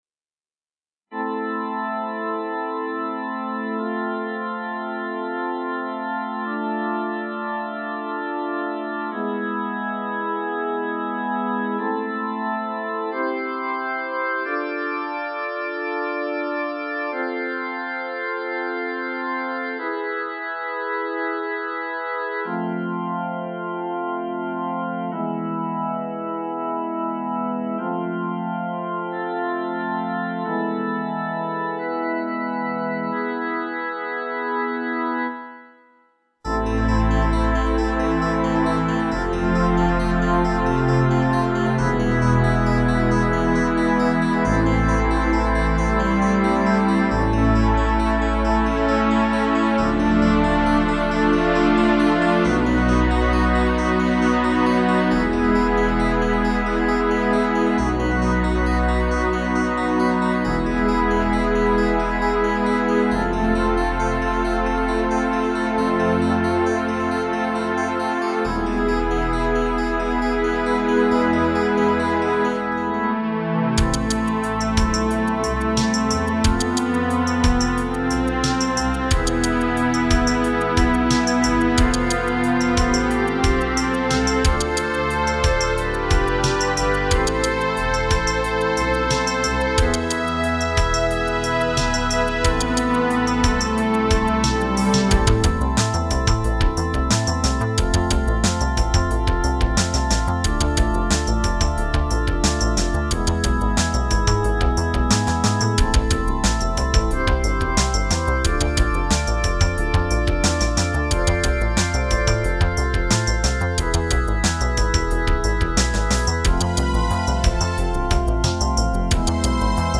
Sound Blaster AWE64